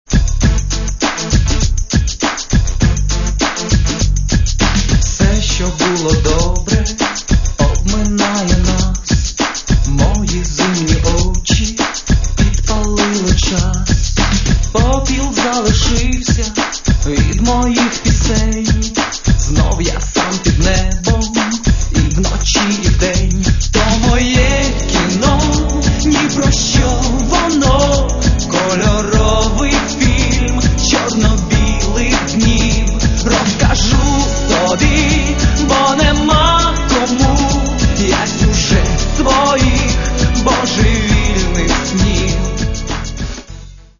Каталог -> Поп (Легкая) -> Сборники
синт-поп и неоромантика